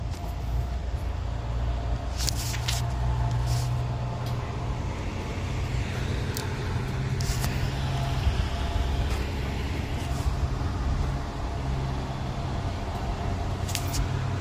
Carretera El Coyol ALAJUELA